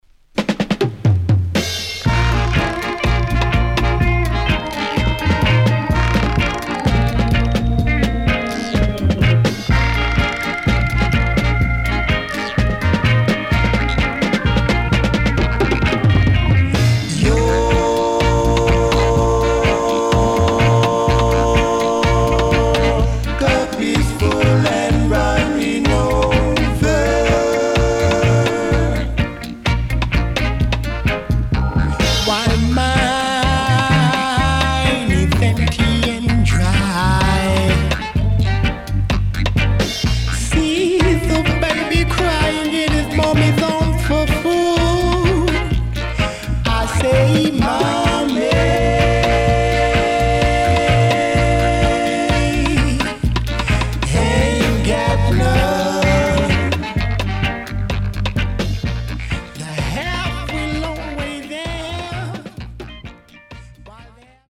Bongo Drummingが効いてるGreat Roots Vocal
SIDE A:少しチリノイズ、プチノイズ入ります。